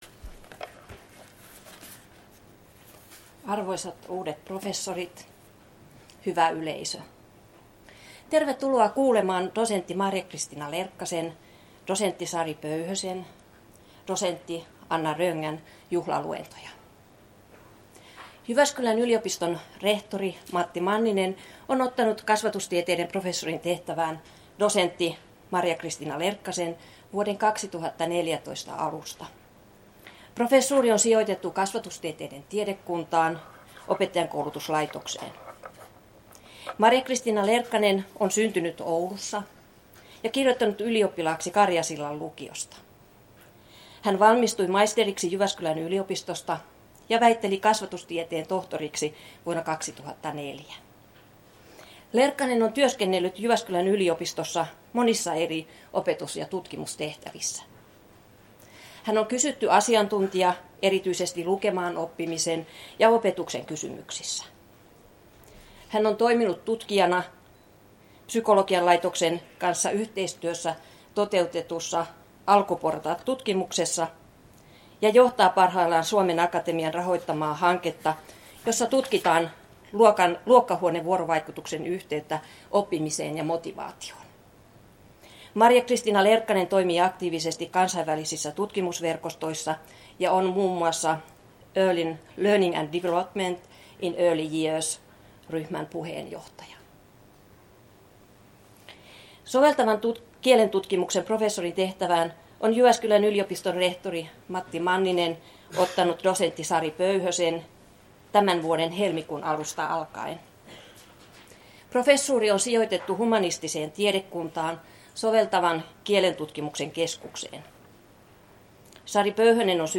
Tilaisuuden avaus L-303